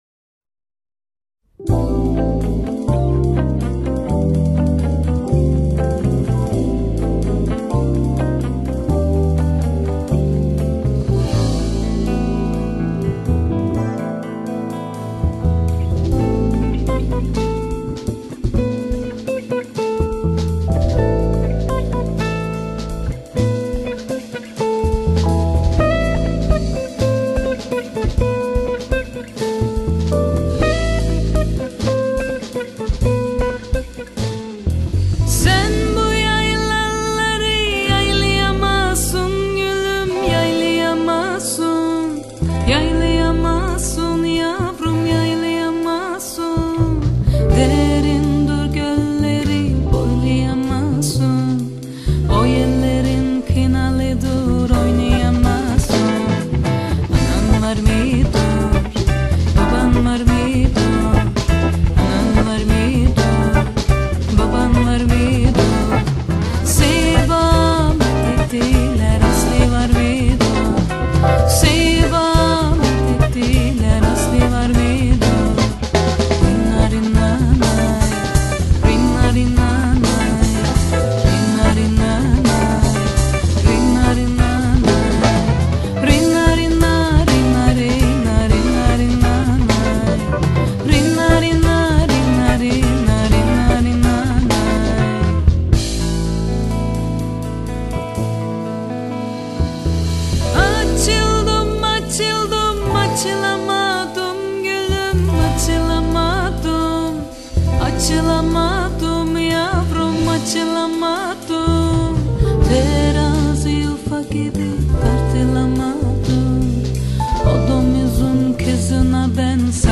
Türkçe sözlü bir caz albümü